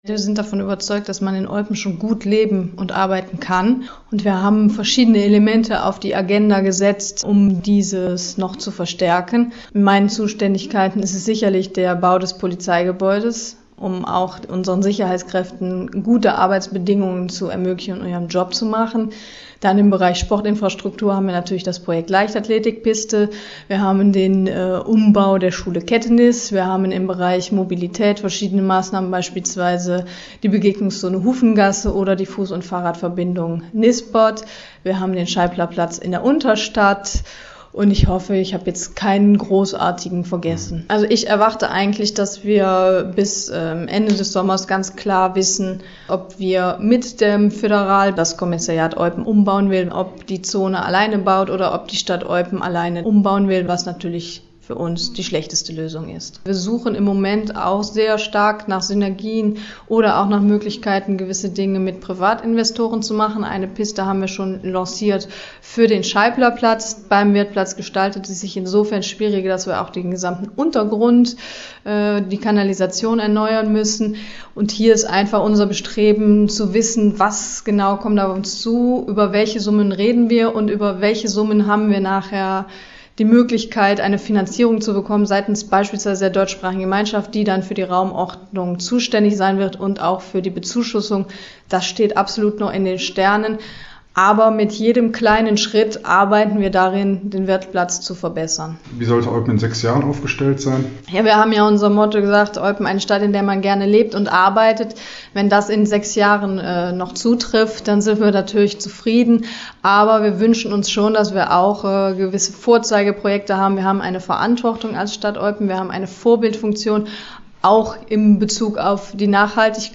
„Daran wollen wir weiter feilen“, so Bürgermeisterin Claudia Niessen im Gespräch